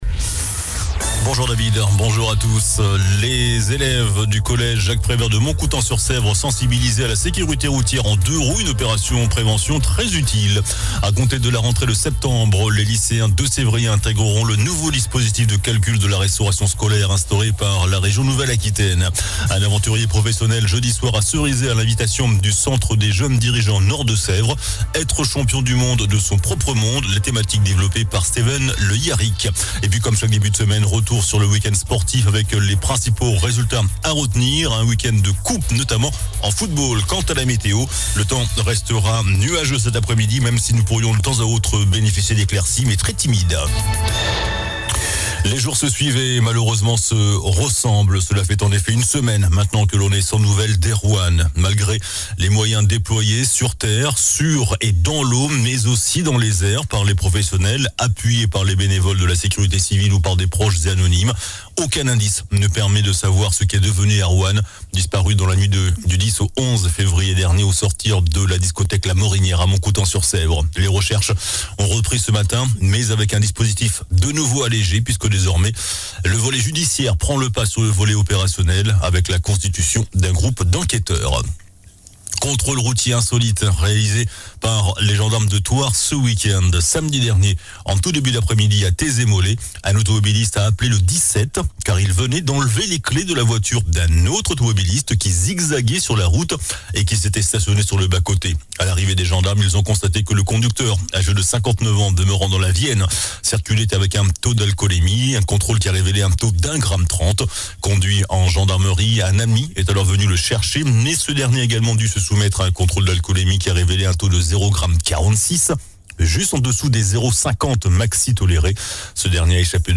JOURNAL DU LUNDI 19 FEVRIER ( MIDI )